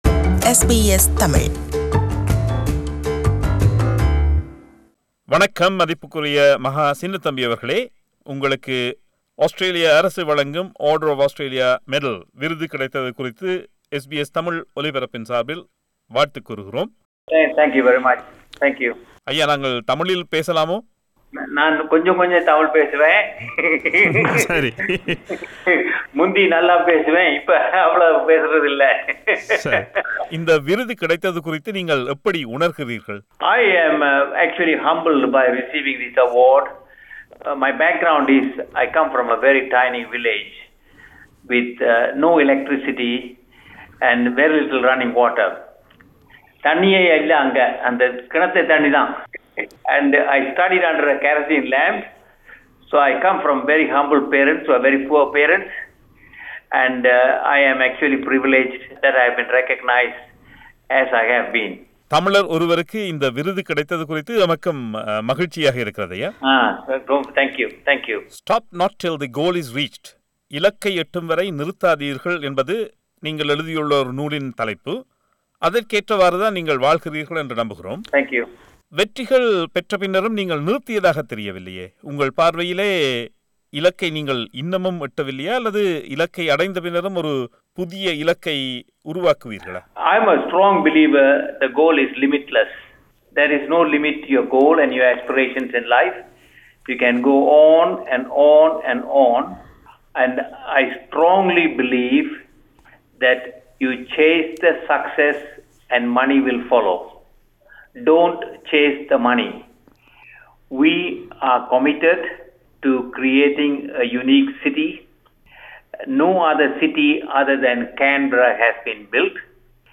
அவரது பின்னணி குறித்தும், ஆஸ்திரேலிய வாழ்க்கை குறித்தும் நேர்காண்கிறார்